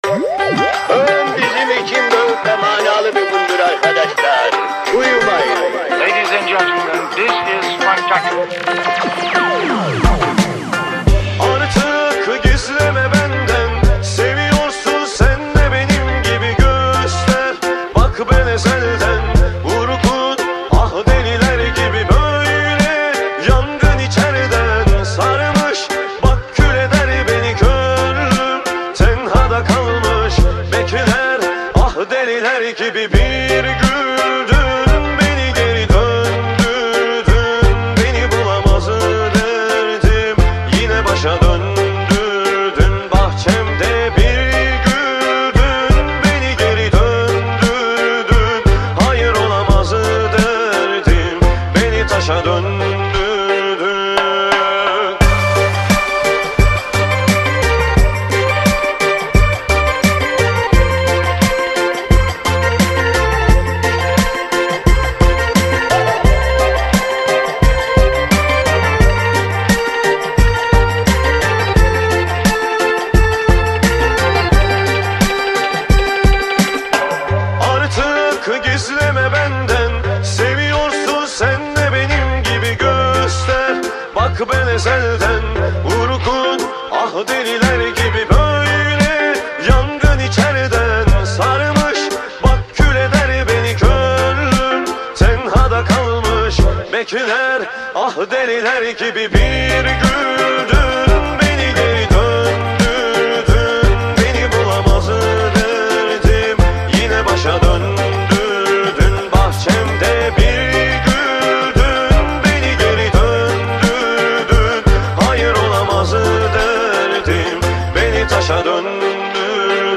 Bu bölümde yapay zekânın günlük yaşam, iş dünyası ve eğitim üzerindeki etkilerini ele alıyoruz. Uzman konuk ile gelecekte bizi bekleyen teknolojik dönüşümleri değerlendiriyoruz.